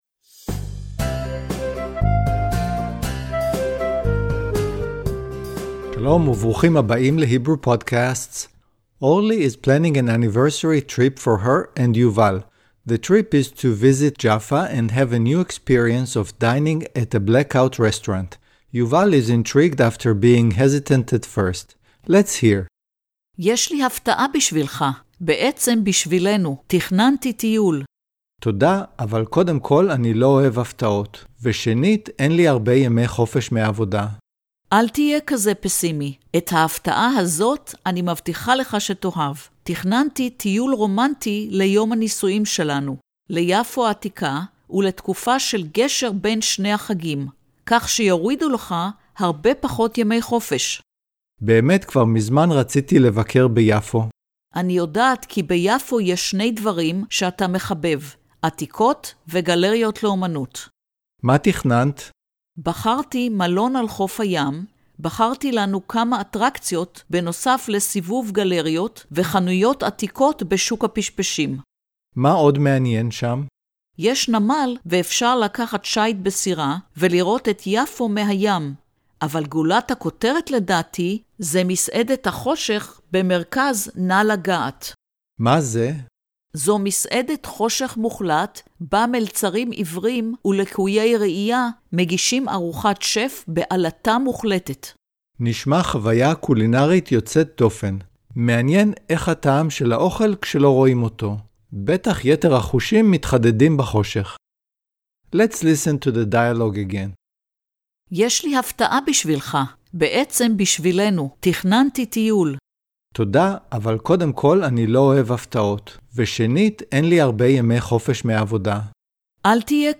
Hebrew Podcasts makes learning conversational Hebrew practical and enjoyable. Each lesson covers a topic with a Hebrew dialog that is explained in detail.